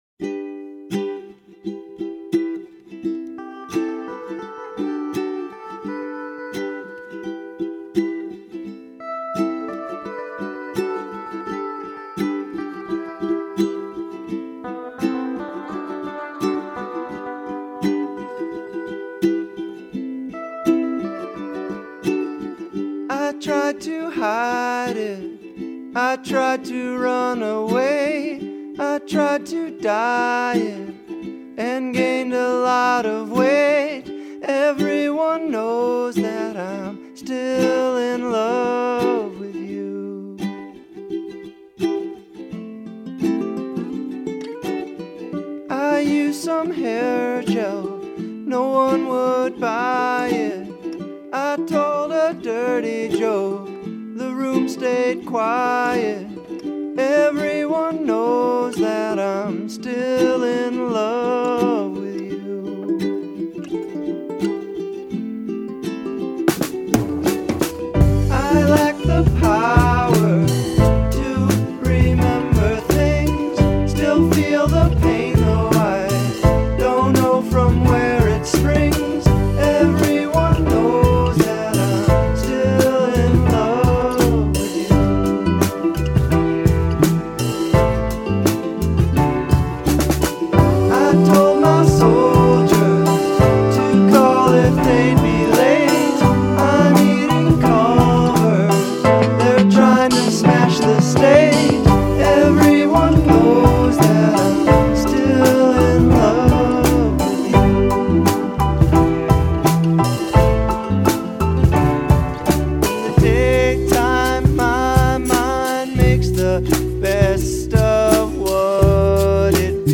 a sweet voice and a multi-instrumentalist